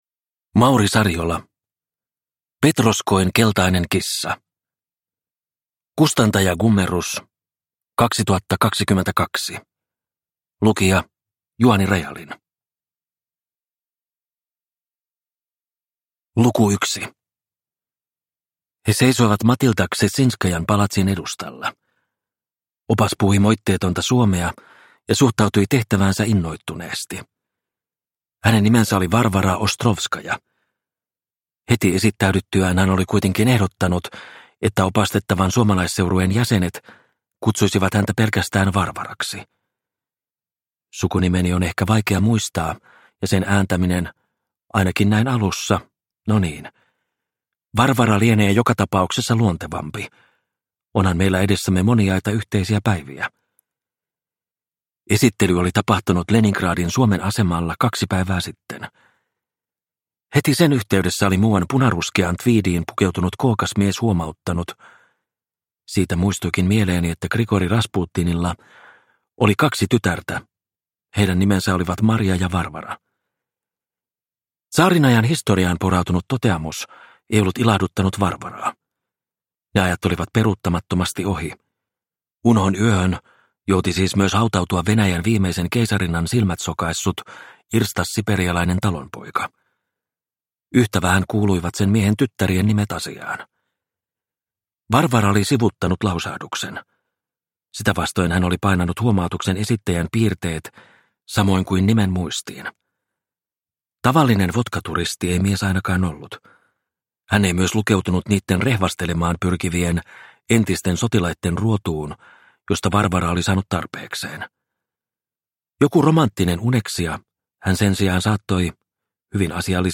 Petroskoin keltainen kissa – Ljudbok – Laddas ner